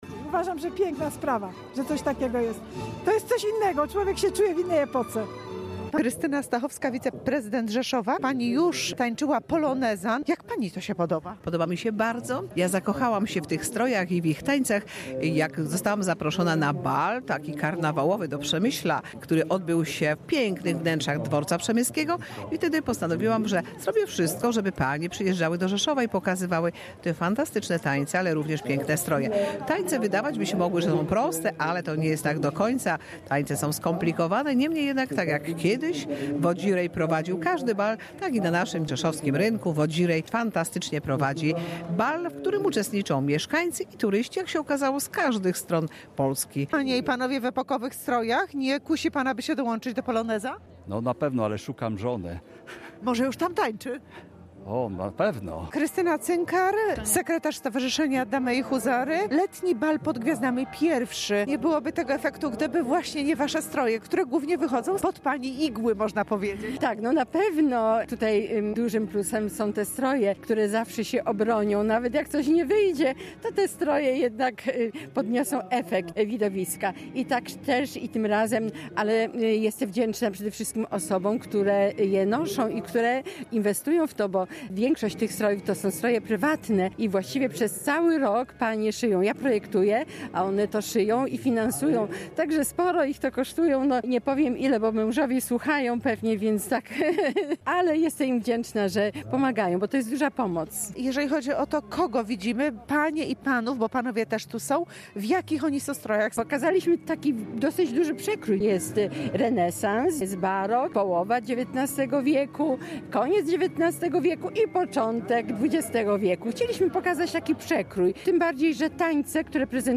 Na płycie rzeszowskiego Rynku królowały walce, kadryle, mazury, polki, kontredanse i polonezy.
Członkowie Stowarzyszenia „Damy i Huzary”, w strojach z przełomu XIX i XX wieku tańczyli do dawnej muzyki dworskiej.
Bal prowadził wodzirej i zachęcał mieszkańców do włączenia się do zabawy.